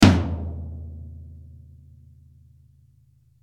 Toms soundbank 5
Free MP3 toms drums sound 5